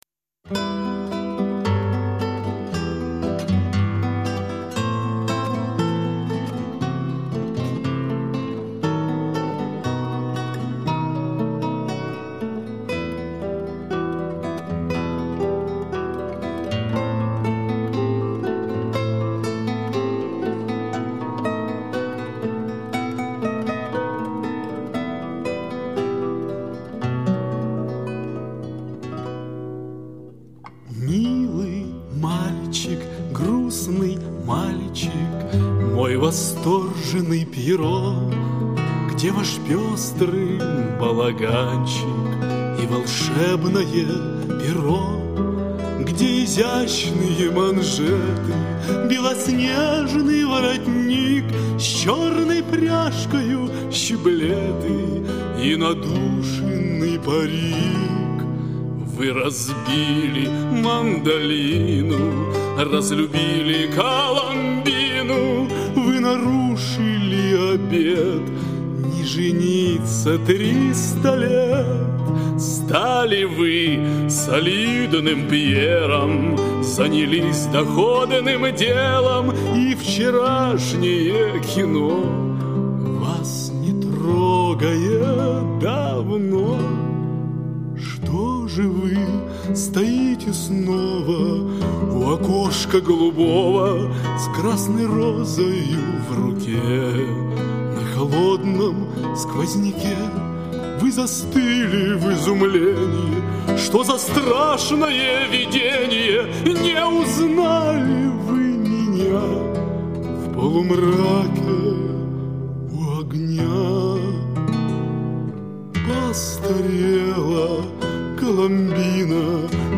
Вы услышите старинные русские романсы, а также романсы, написанные самим исполнителем на стихи поэтов золотого и серебряного века.
гитара, вокал